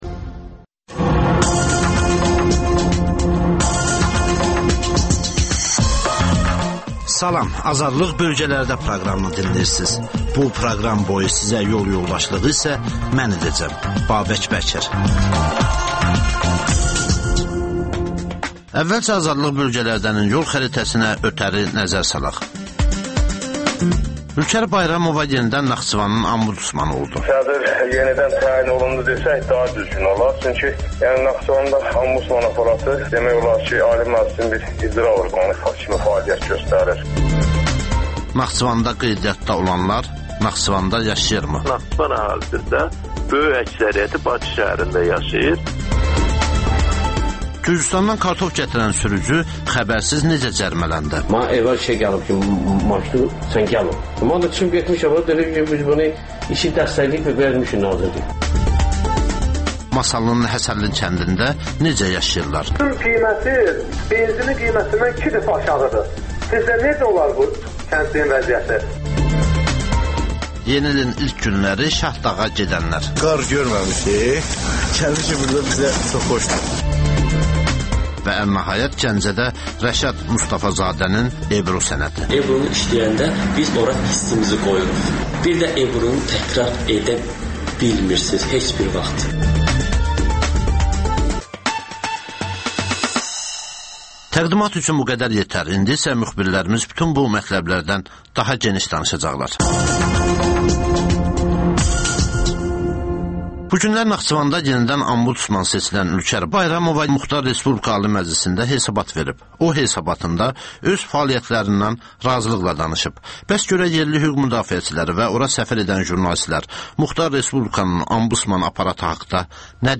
Rayonlardan xüsusi reportajlar.